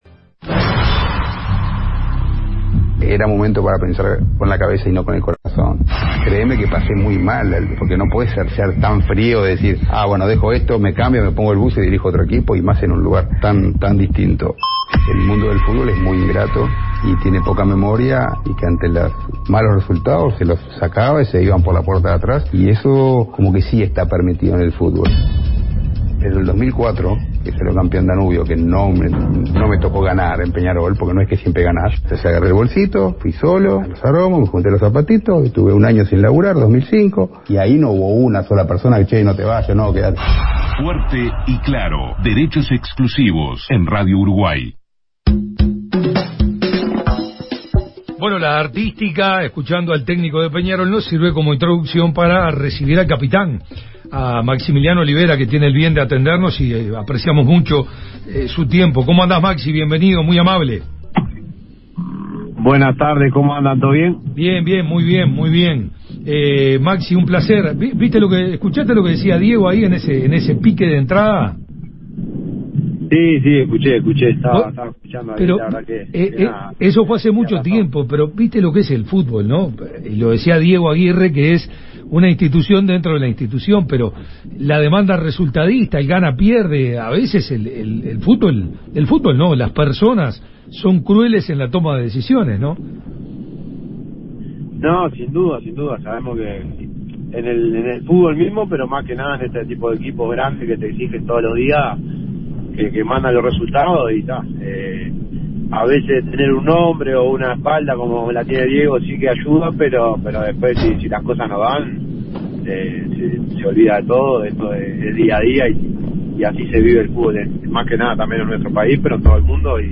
El capitán de Peñarol habló en Derechos Exclusivos sobre el triunfo frente a Racing en el Parque  Viera: “Fue un partido difícil en el primer tiempo porque ellos buscaban al 9 que pivoteaba bien y jugaba para sus extremos que llegaban rápido, pero nosotros cuando hicimos los goles nos encontramos como equipo. Después en el segundo tiempo manejamos el partido como queríamos y fuimos justos vencedores”.